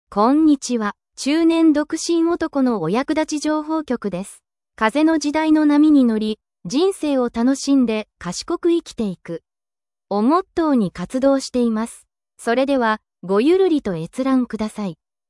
音声生成
「AIエンジン」「音声モデル」を選択、しゃべらせたいことをテキストプロンプトを日本語入力して、「音声を生成」をクリックします。